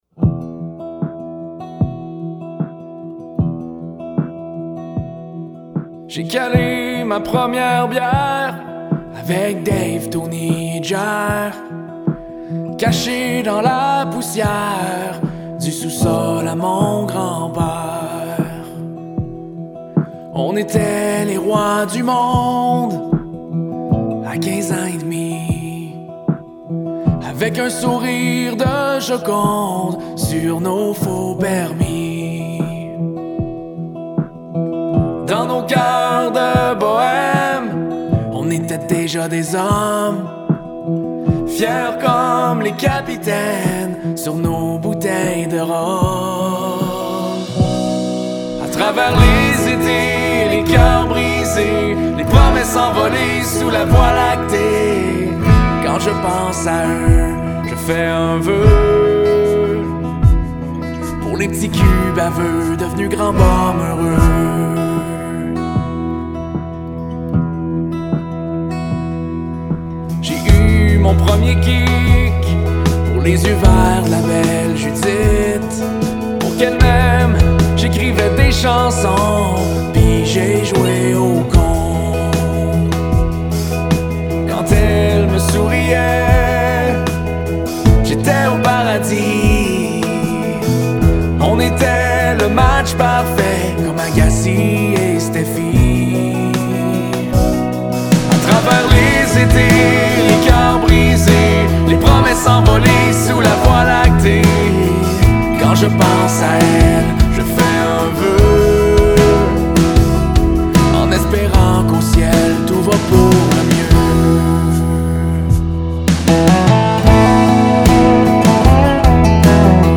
Genre : Country.